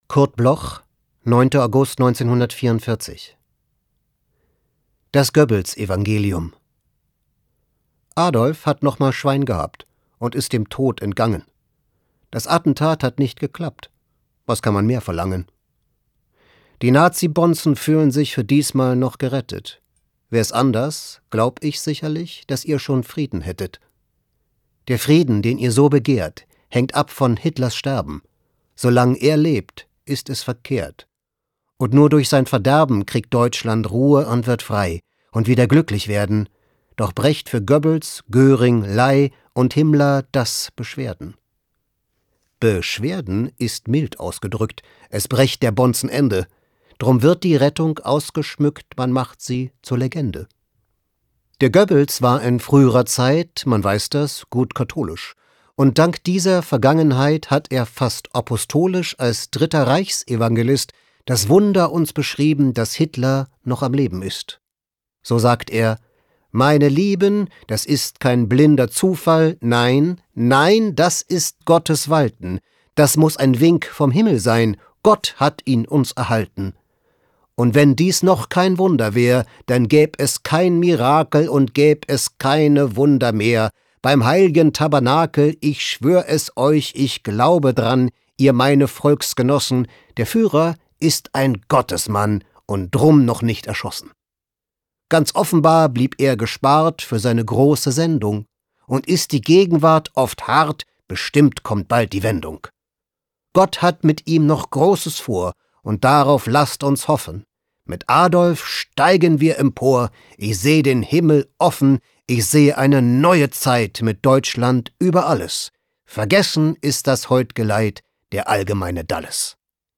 Florian Lukas (* 1973) ist ein deutscher Schauspieler.